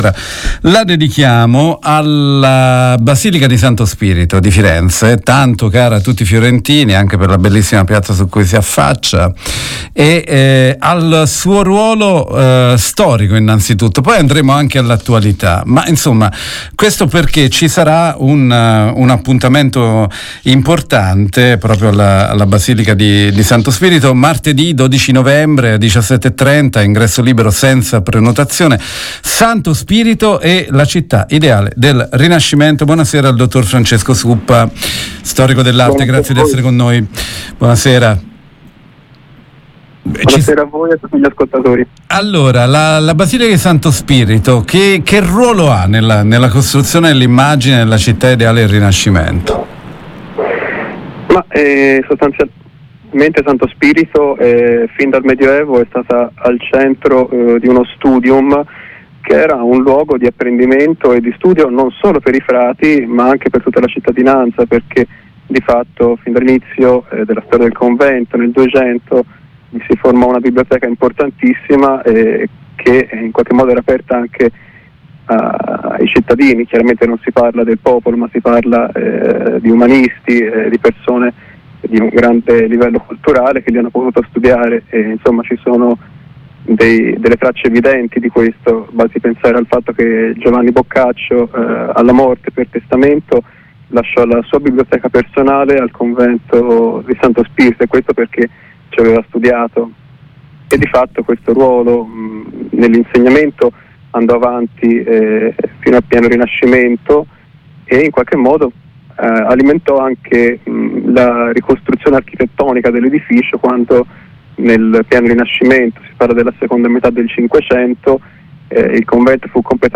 La conferenza offrirà una visione approfondita del ruolo storico e culturale del complesso di Santo Spirito e sarà un’occasione per esplorare una proposta di valorizzazione che mira a recuperare l’unità architettonica e culturale del sito, in linea con le recenti riflessioni dell’UNESCO sull’importanza della conservazione dei complessi monumentali. Intervista